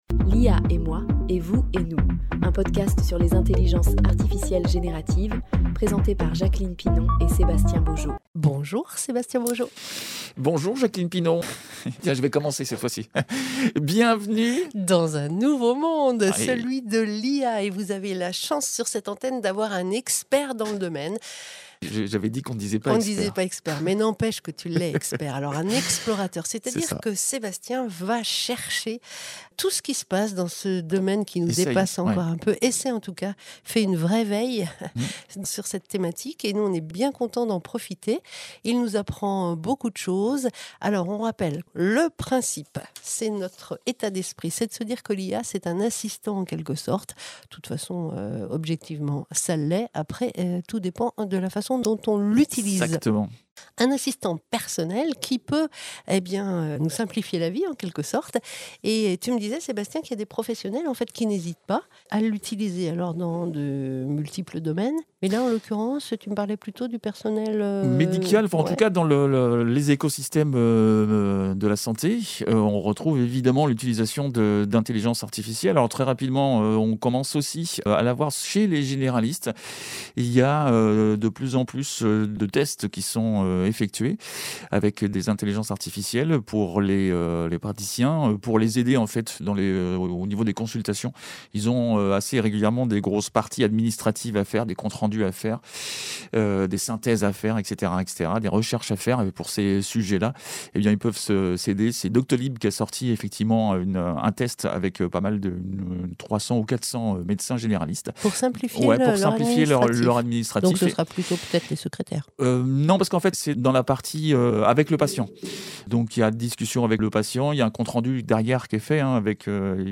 Un podcast de vulgarisation des IA génératives 0:00 0:00